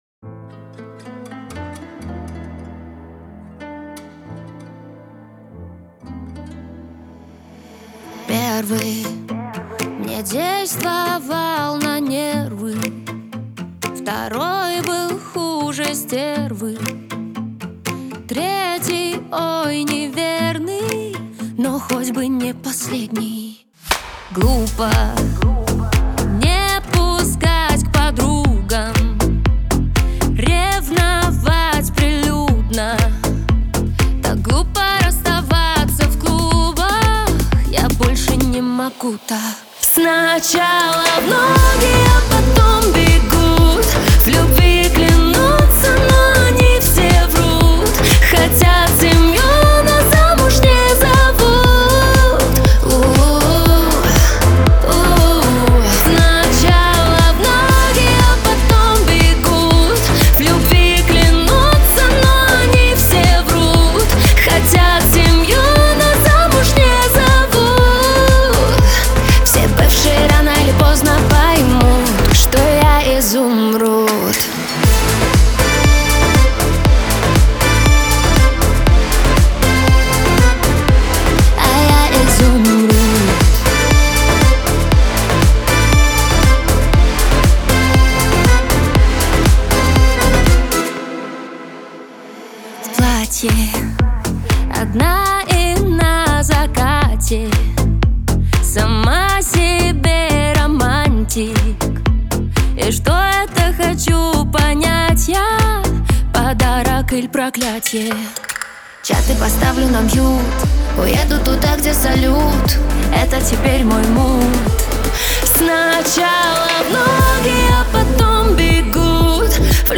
эстрада , pop